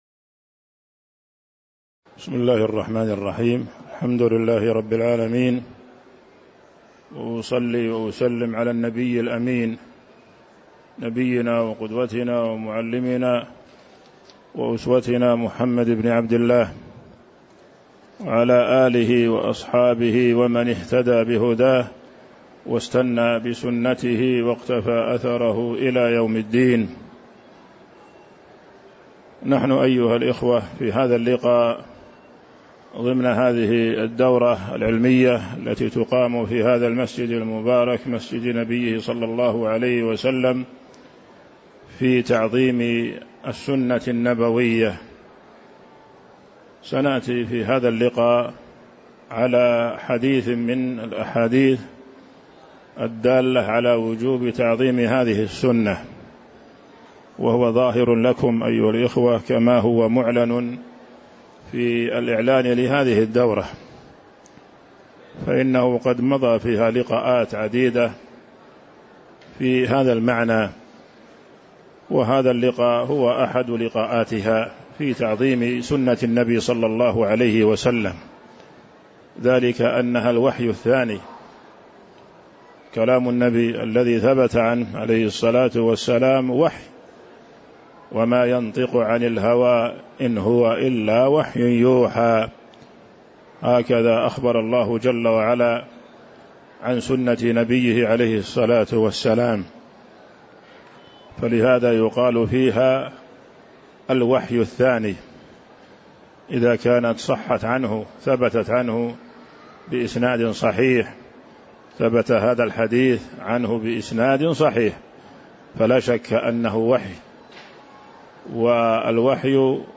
تاريخ النشر ١٤ ربيع الثاني ١٤٤٦ هـ المكان: المسجد النبوي الشيخ